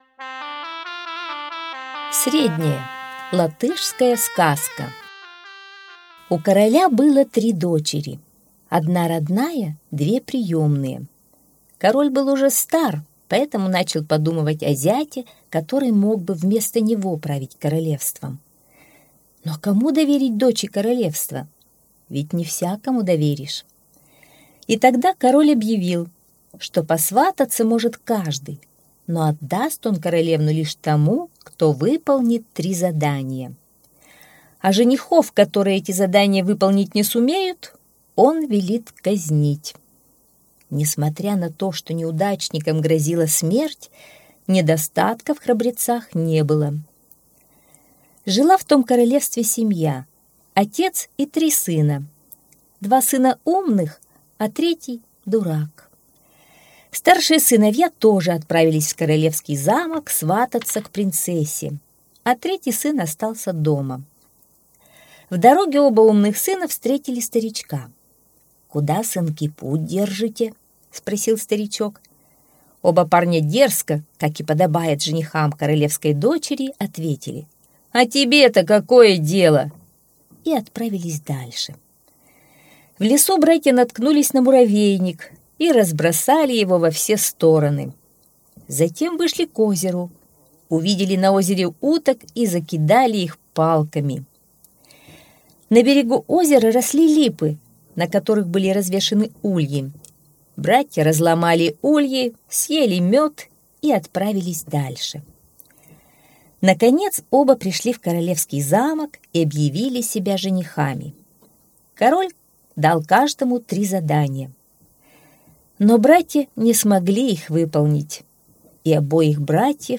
Средняя - латышская аудиосказка - слушать онлайн